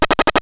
effect_poison.wav